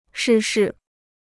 逝世 (shì shì): เสียชีวิต; ตาย.